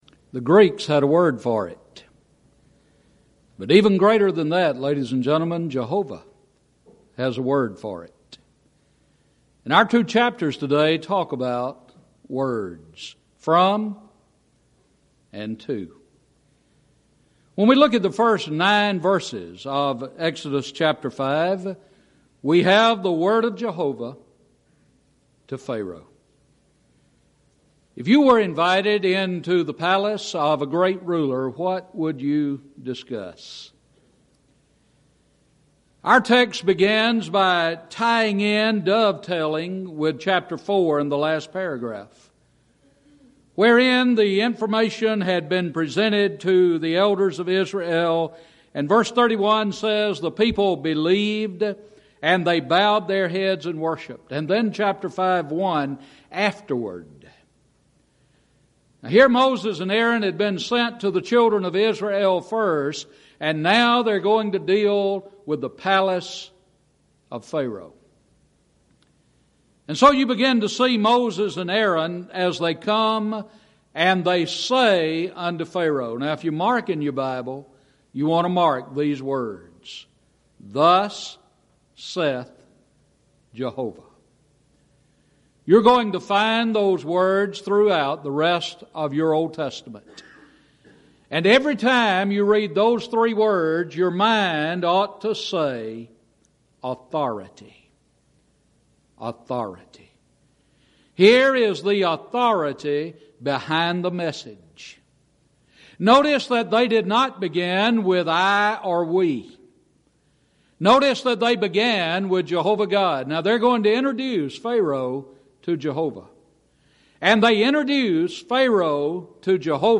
Event: 2nd Annual Schertz Lectures
lecture